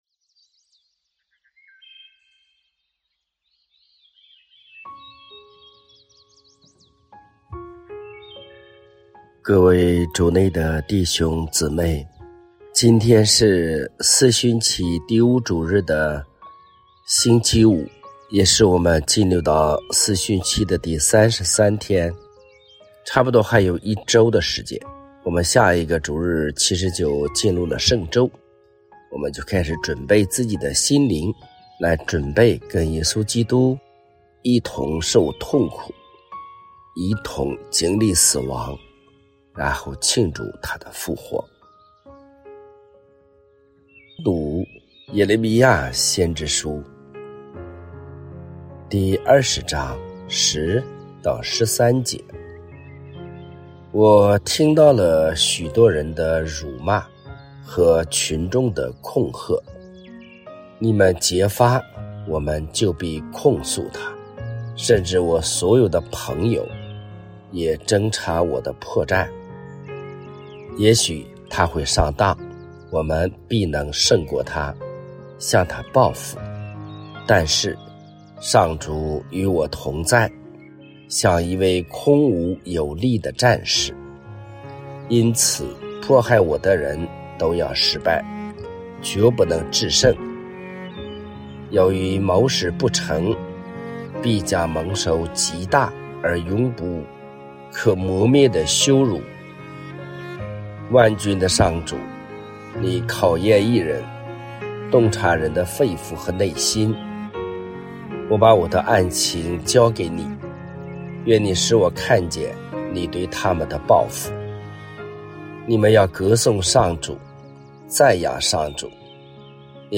每天来打卡，听神父讲道理，神父最近嗓子不舒服，记着多喝水！